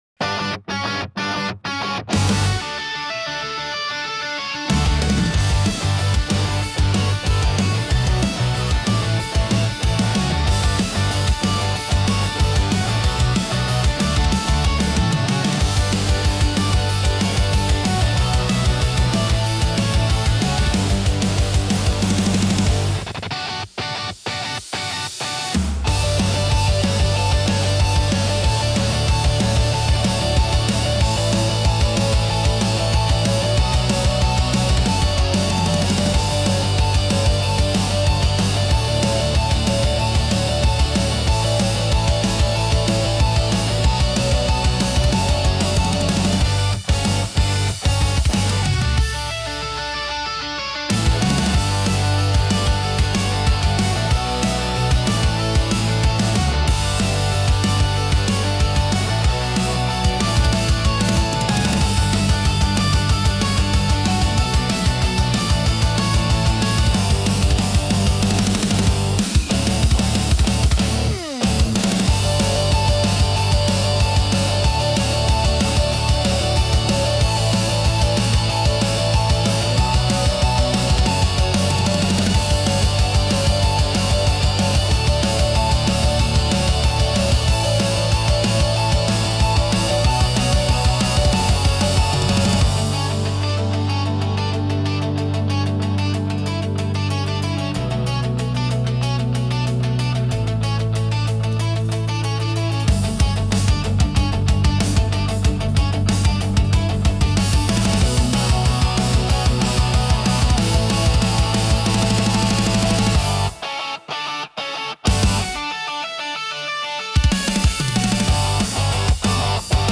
エネルギッシュ・アップビートロック・ボーカル無し
インストゥルメンタル アップテンポ ロック 明るい 元気